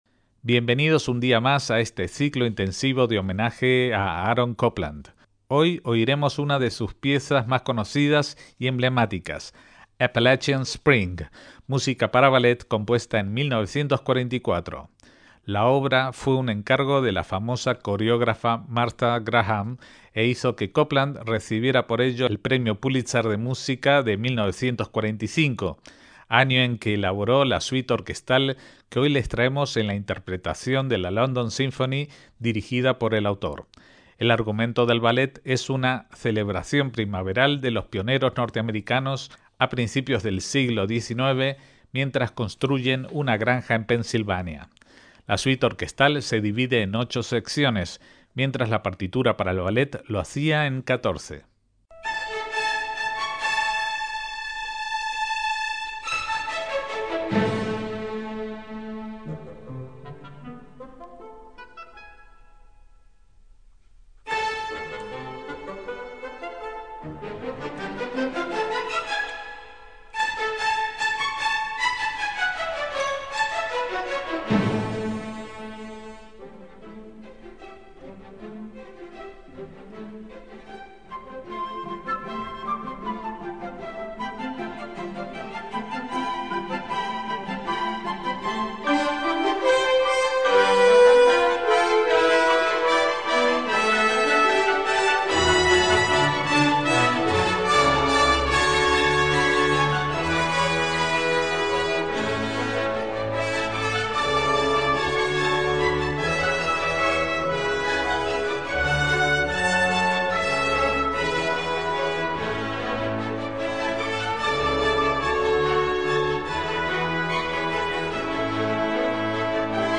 suite orquestal